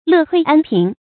樂退安貧 注音： ㄌㄜˋ ㄊㄨㄟˋ ㄢ ㄆㄧㄣˊ 讀音讀法： 意思解釋： 謂樂于遜退，安于貧窮。